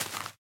minecraft / sounds / dig / grass1.ogg
grass1.ogg